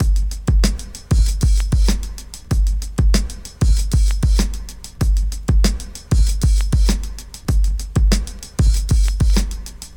• 96 Bpm Fresh Breakbeat G Key.wav
Free drum groove - kick tuned to the G note. Loudest frequency: 876Hz
96-bpm-fresh-breakbeat-g-key-qMy.wav